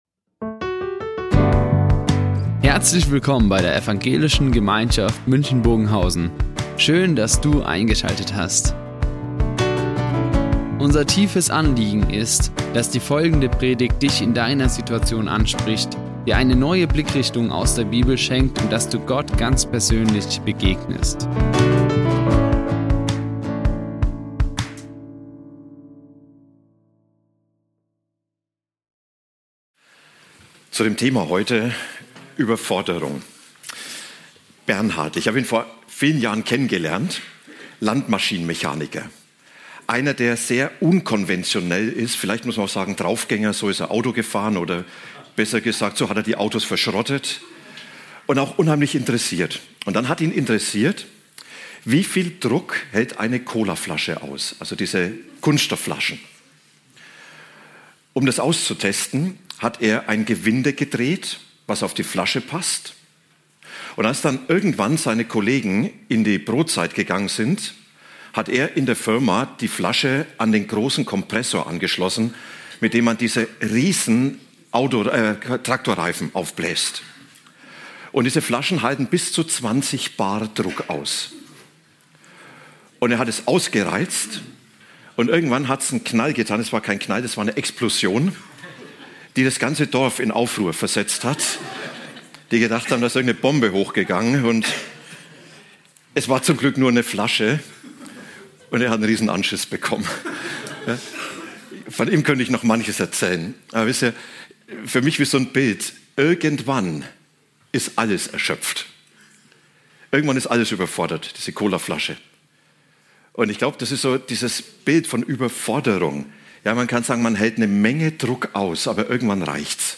EinSPRUCH gegen die Überforderung | Predigt Matthäus 11,28 und Lukas 21,28 ~ Ev.